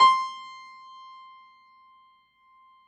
53h-pno18-C4.wav